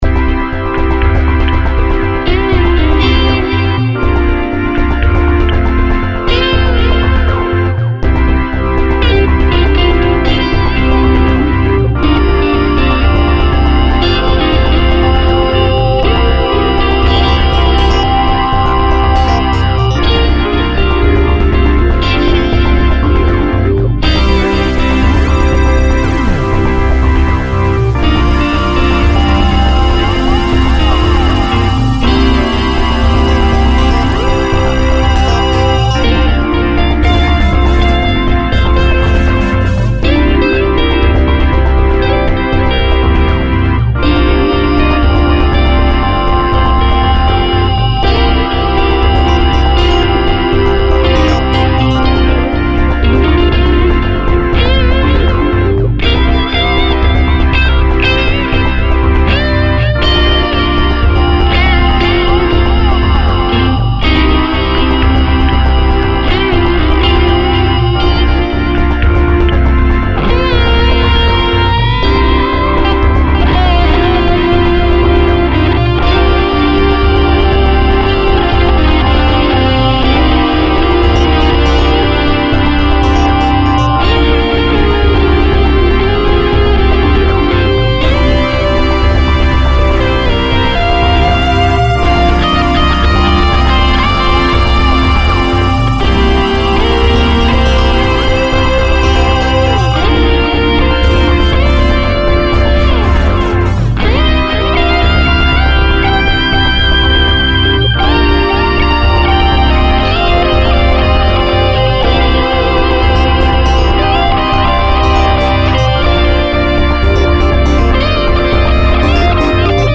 Vor ein paar Wochen habe ich meinen JTM45 mit der 4x12 ins Studio gestellt und mit meiner alten Tokai Strat was dazu eingespielt. Bei den kurzen Einwürfen rechts war noch mein Vibe im Einsatz, bei der Solospur kommt später der Colorsound ToneBender dazu, im Mix dann noch ein klein wenig Tape-Delay.
Diesmal habe ich nicht nur Gitarre aufgenommen, sondern noch ein bischen mit Synthi-Plug-Ins herumexperimentiert. Abschließend habe ich alles noch durch einen Multiband Kompressor gejagt.